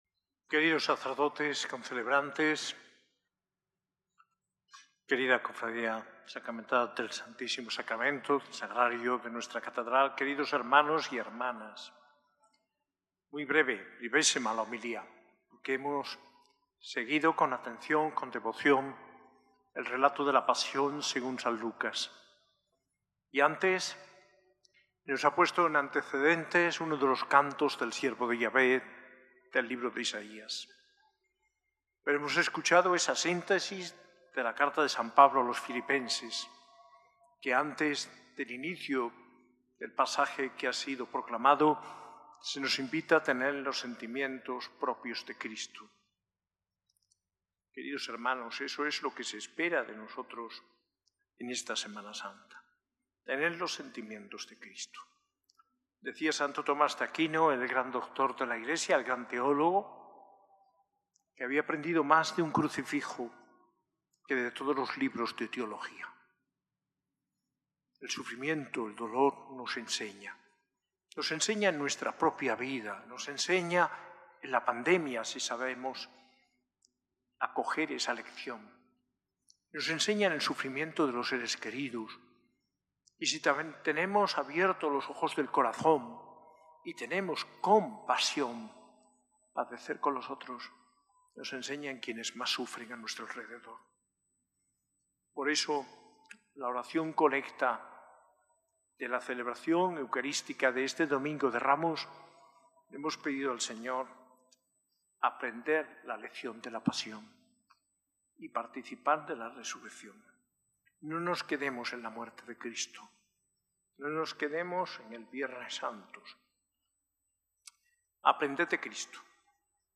Homilía del arzobispo de Granada, Mons. José María Gil Tamayo, en el Domingo de Ramos, en la S.A.I Catedral el 13 de abril de 2025.